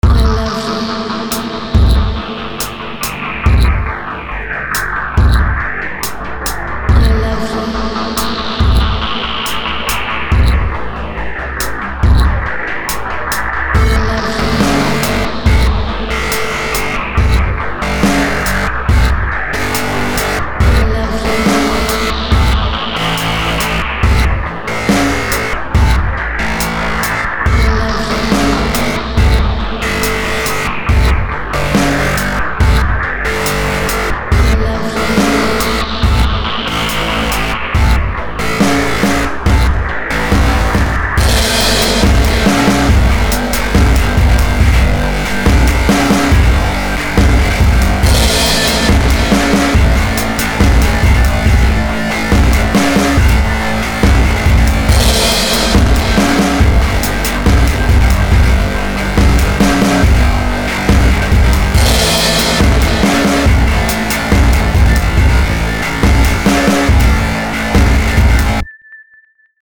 grind